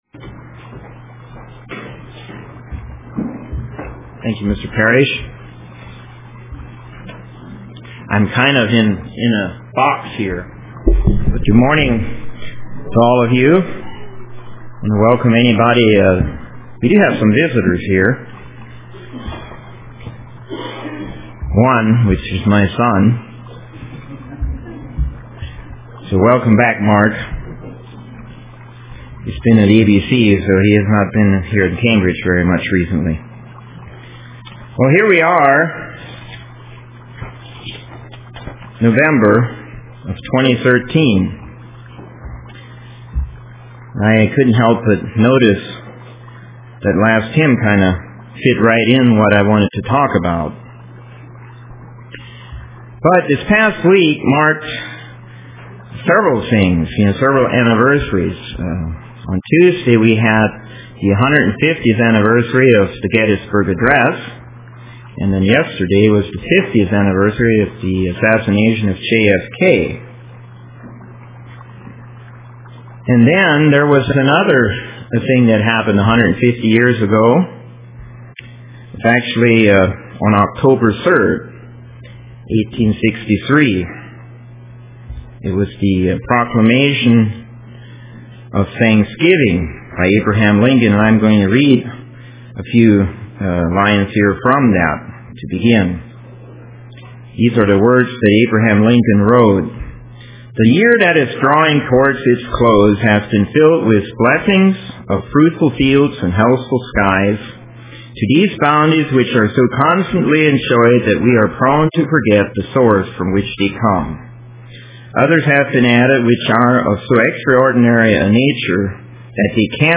Print Give Thanks UCG Sermon Studying the bible?